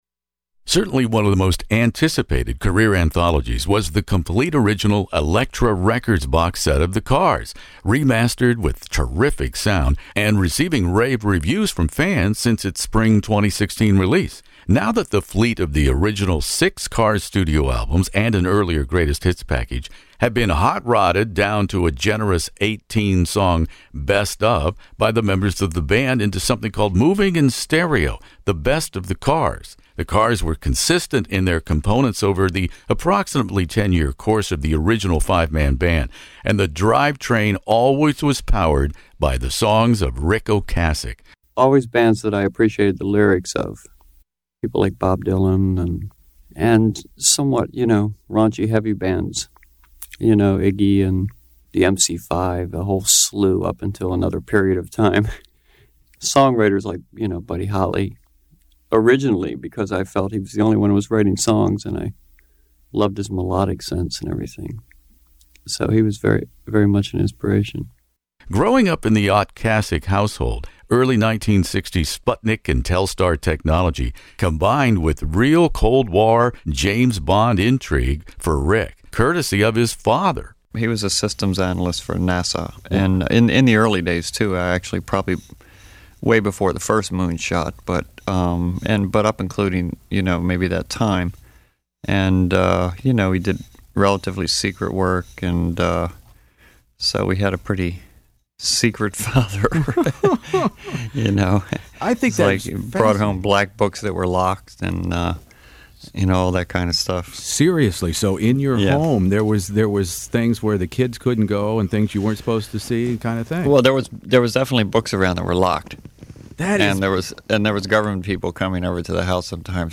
Ric, who sadly passed away from heart disease in 2019, and Greg Hawkes are my guests In the Studio in this classic rock interview.